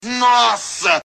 Seu Madruga (Chaves) exclamando 'Nossa'